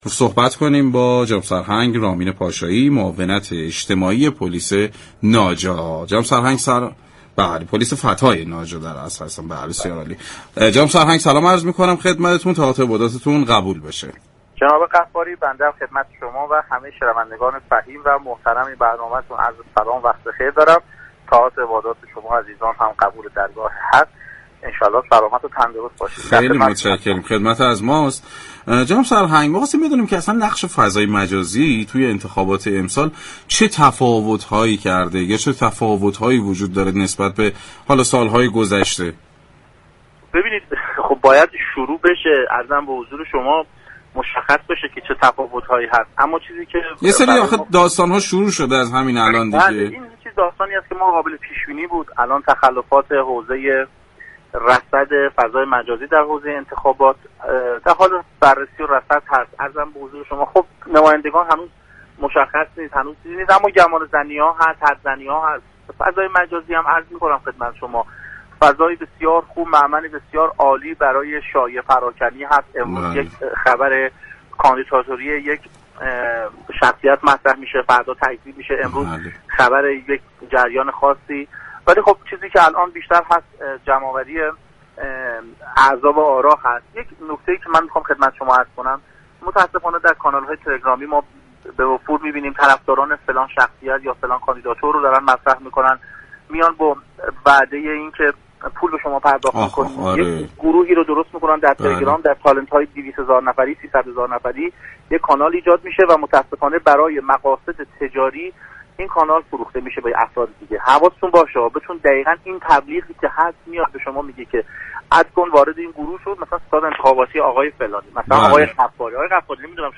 به گزارش پایگاه اطلاع رسانی رادیو تهران، سرهنگ رامین پاشایی معاون اجتماعی پلیس فتای ناجا در گفتگو با برنامه سعادت آباد در توضیح نقش فضای مجازی در داغ كردن فضای انتخابات سال جاری گفت: آنچه در حال حاضر بسیار مهم است ایجاد كالنال های مختلف جهت جمع آوری آراء برای كاندیداهای مشخصی است.